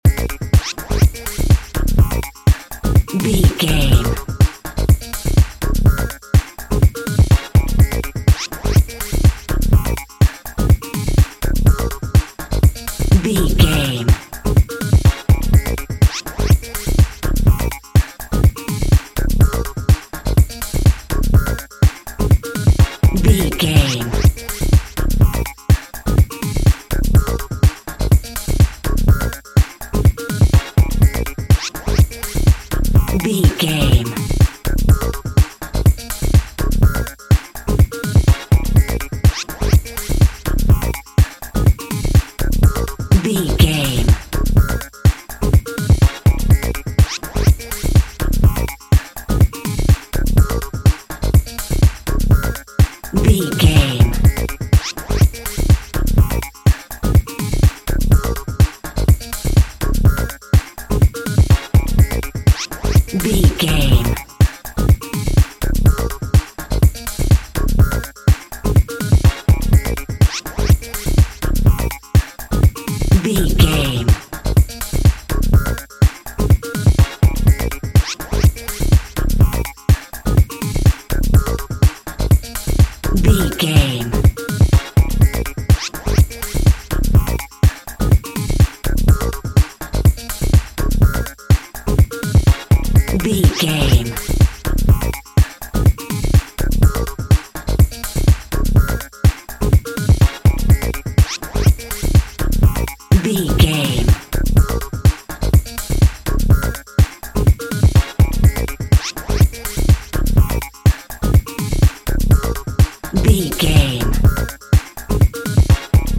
Aeolian/Minor
C#
smooth
futuristic
industrial
drum machine
synthesiser
brass
bass guitar
instrumental music
synth drums
synth leads
synth bass